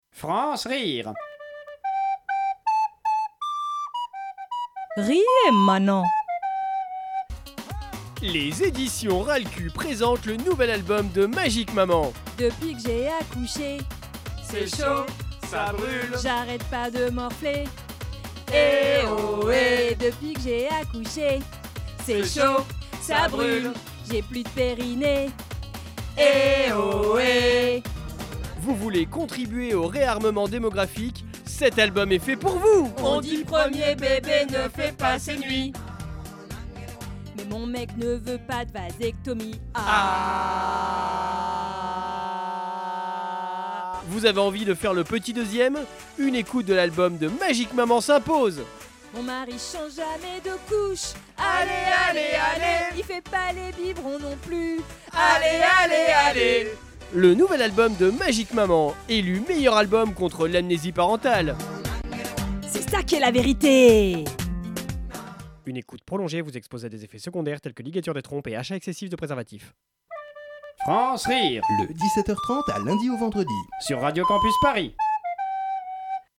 Création sonore